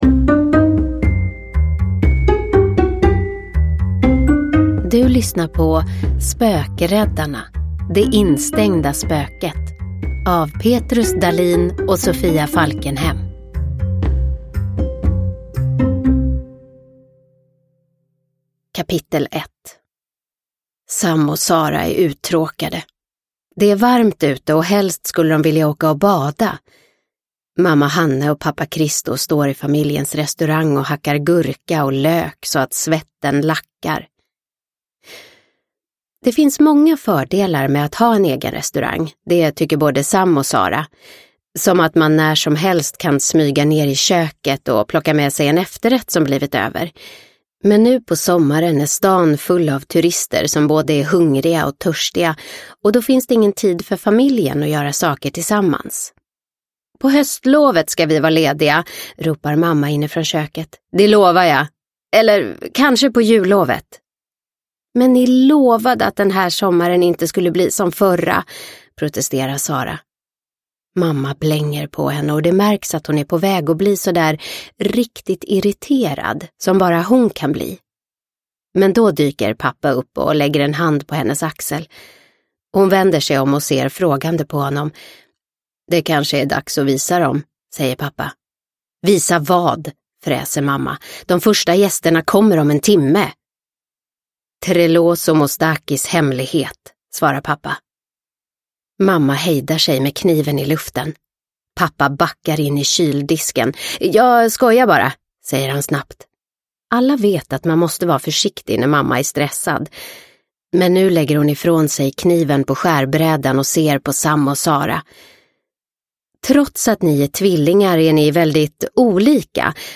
Det instängda spöket – Ljudbok – Laddas ner